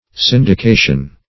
Syndication \Syn`di*ca"tion\, n.